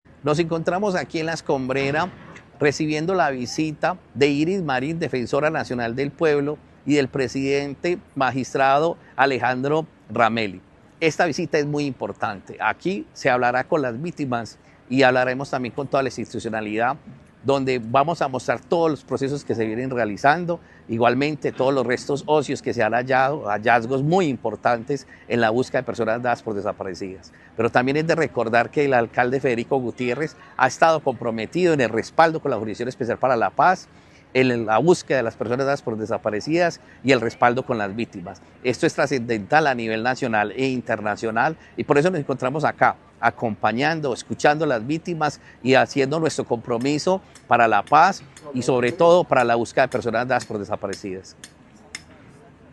Palabras de Carlos Alberto Arcila, secretario de Paz y Derechos Humanos